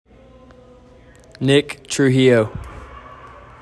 Pronunciation:  NICK true HE yo